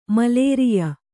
♪ mlēriya